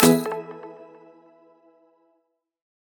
melodic-4.wav